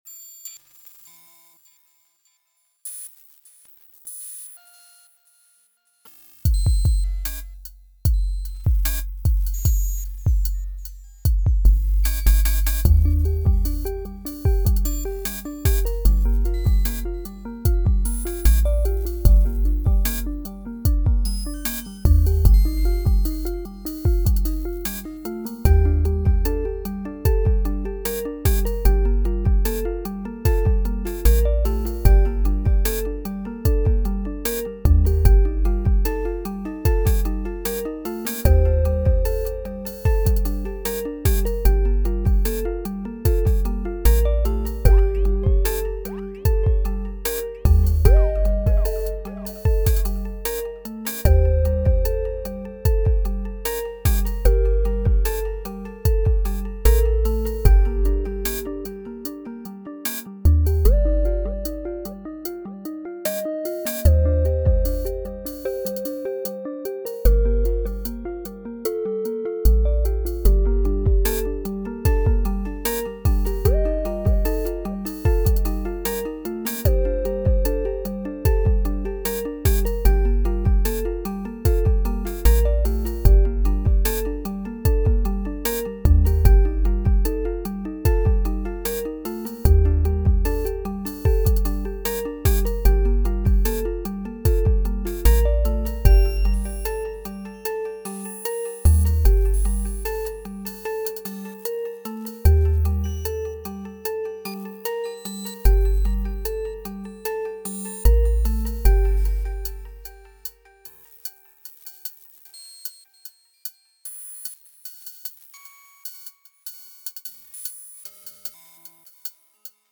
Just jamming with one pattern, muting and unmuting. Occasionally confusing myself and unmuting the wrong things and tweaking the delay times at the wrong time so this is a warts and all situation!